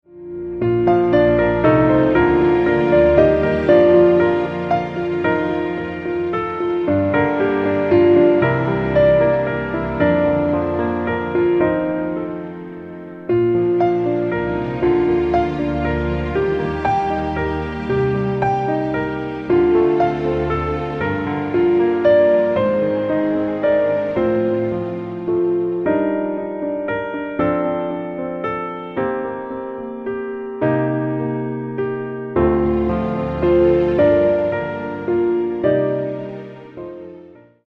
Piano - Strings - Low